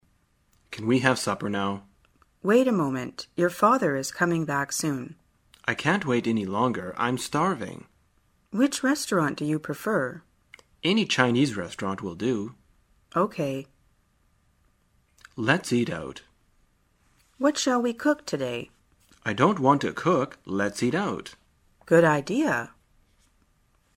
在线英语听力室生活口语天天说 第7期:怎样谈论吃饭的听力文件下载,《生活口语天天说》栏目将日常生活中最常用到的口语句型进行收集和重点讲解。真人发音配字幕帮助英语爱好者们练习听力并进行口语跟读。